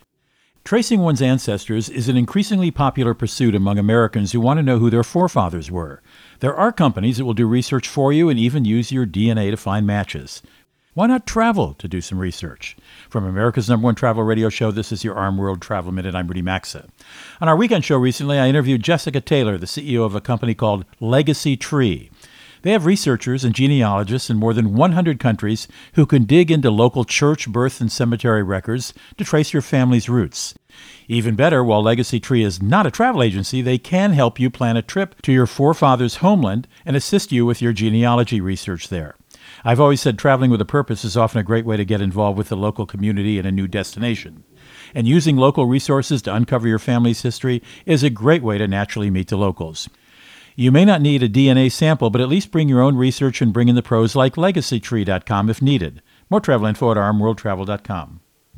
America's #1 Travel Radio Show
Co-Host Rudy Maxa | Genealogy Travel